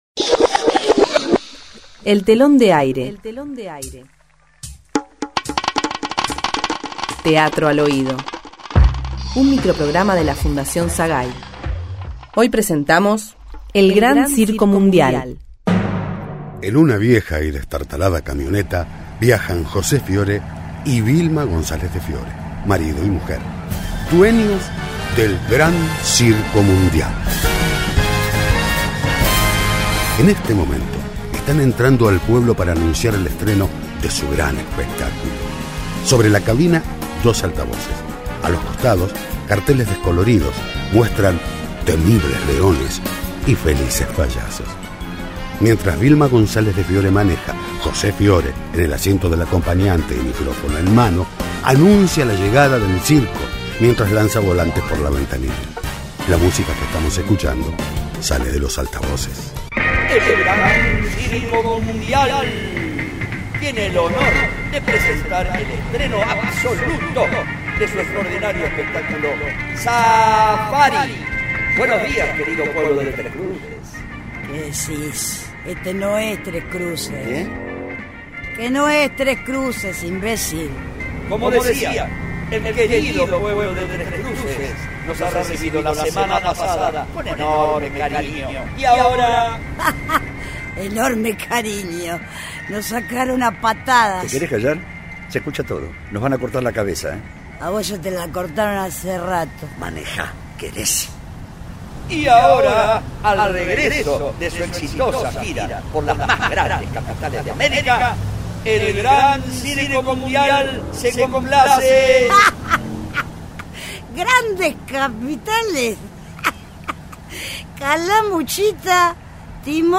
Título: El gran circo mundial. Género: Ficción. Sinopsis: Un nuevo pueblo, la misma eterna gira.
grabación en estudio